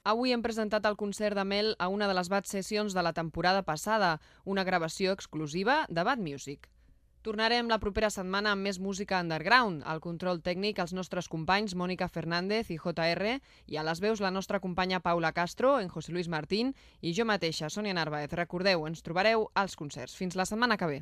Comiat i crèdits del programa.
Musical